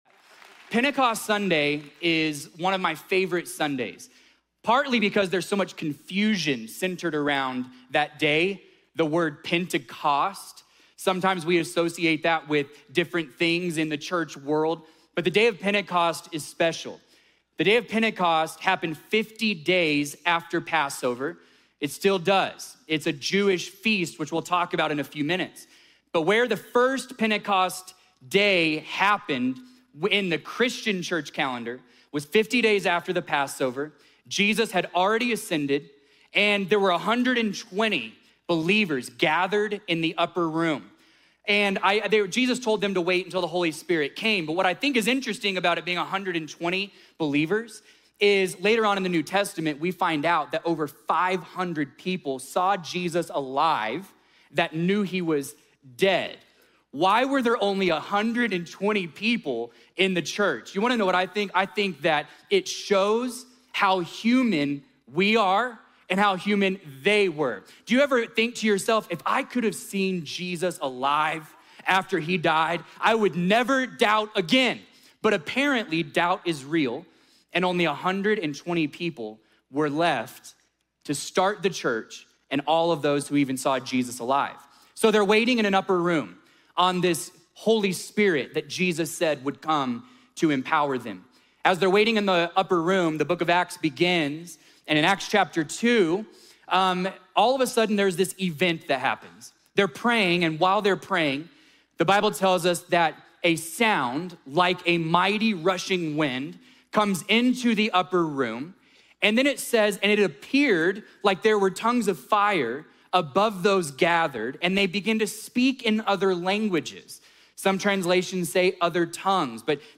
A message from the series "Topical Teachings."